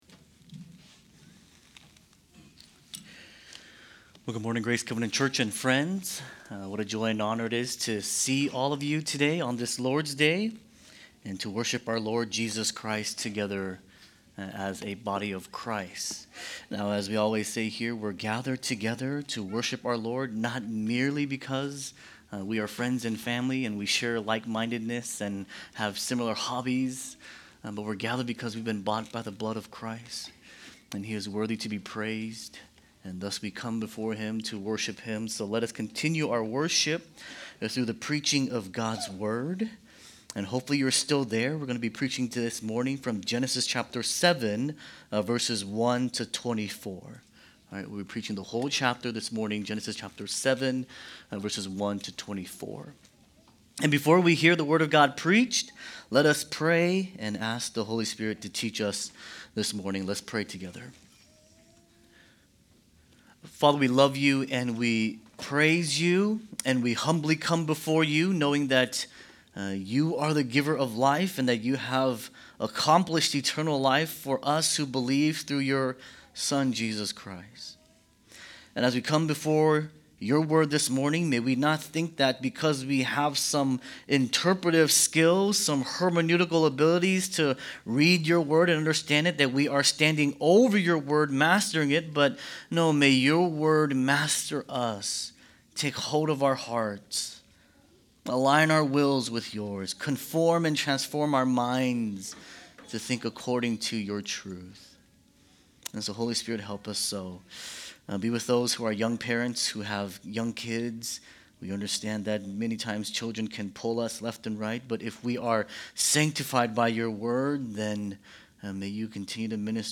Sermons | Grace Covenant Church